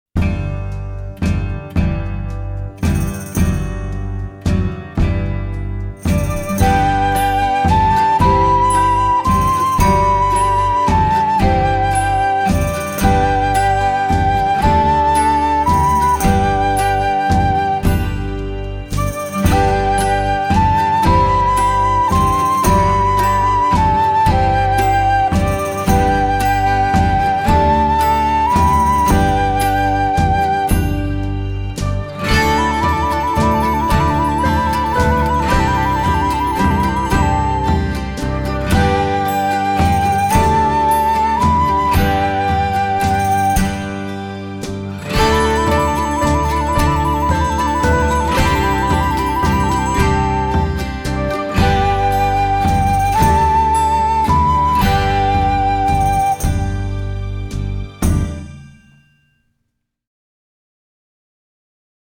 Voicing: Recorder Collection